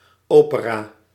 Ääntäminen
US : IPA : [ˈoʊ.pər.ə]